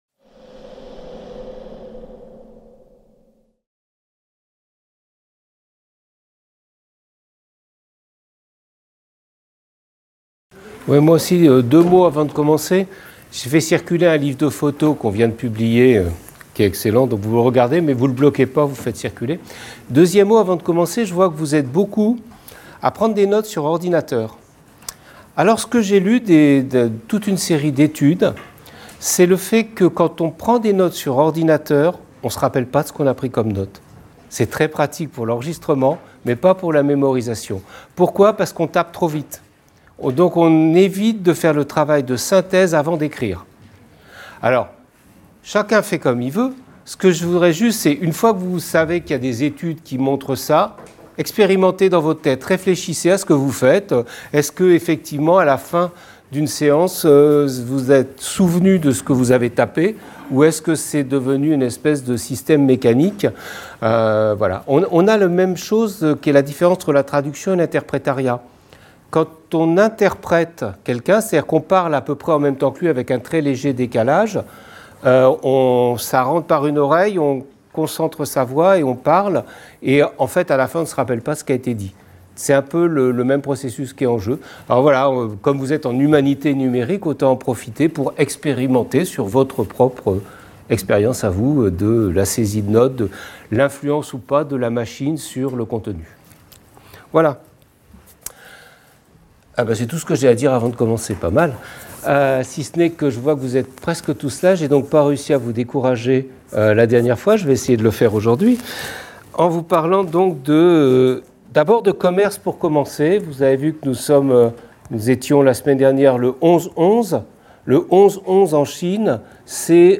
Cours de Culture numérique dans le cadre de la Licence Humanités parcours Humanités numériques.